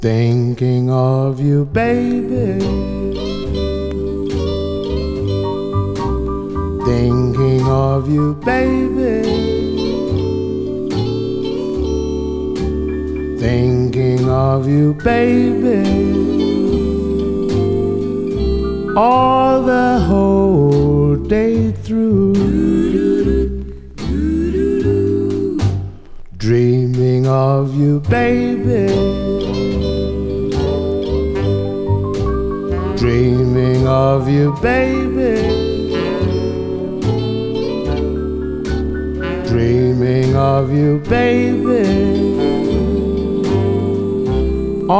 シンプルで小技の効いた演奏、個性を放つサックスにギター。
R&B, Jazz, Jump　France　12inchレコード　33rpm　Mono